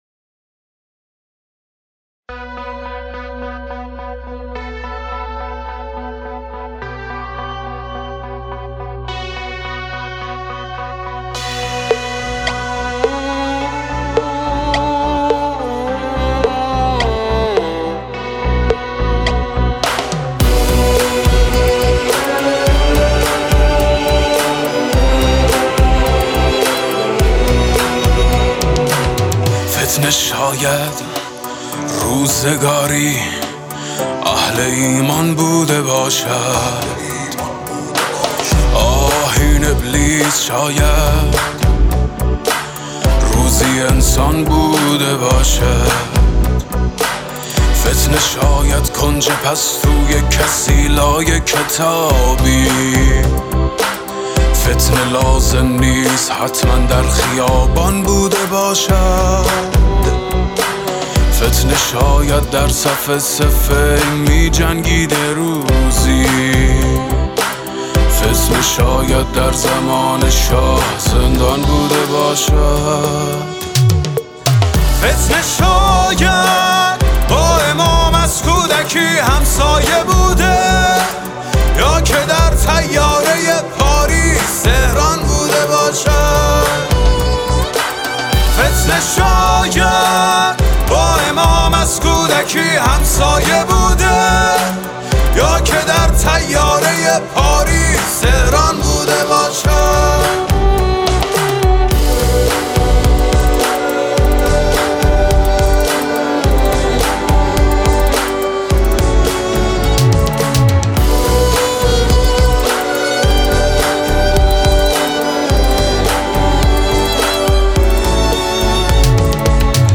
آهنگ